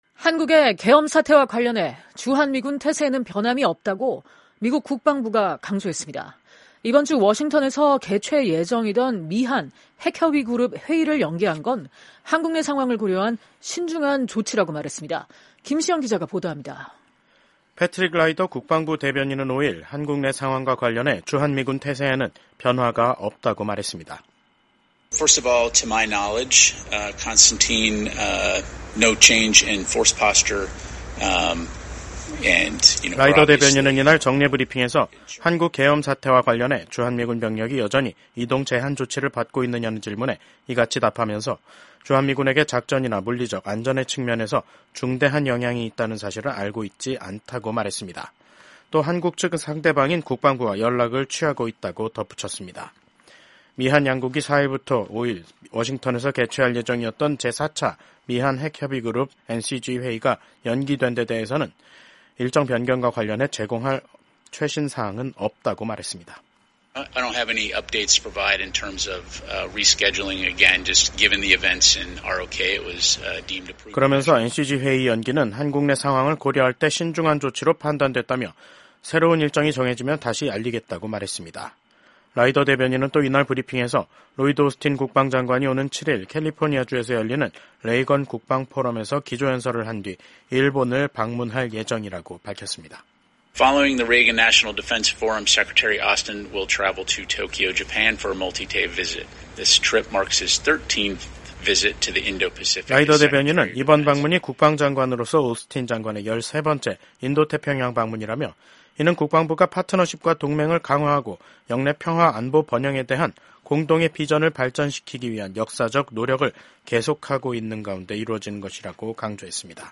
팻 라이더 미국 국방부 대변인.